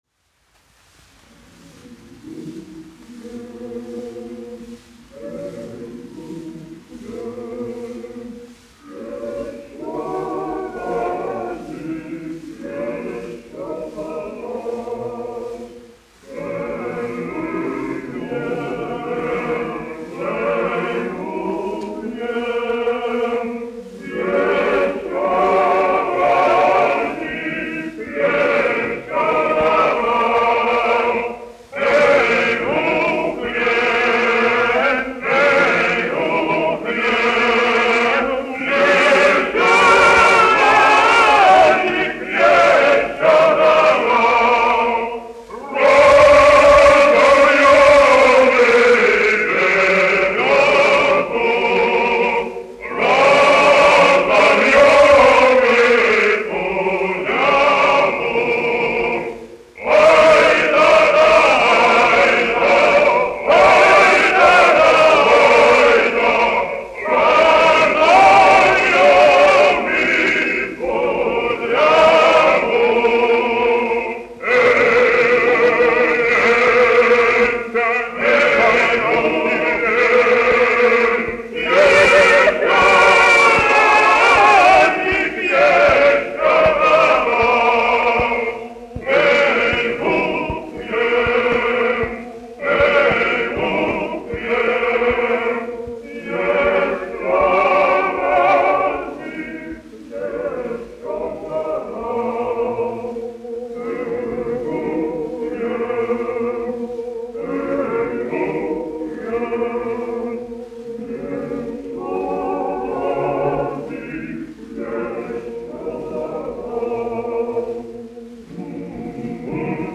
1 skpl. : analogs, 78 apgr/min, mono ; 25 cm
Krievu dziesmas
Skaņuplate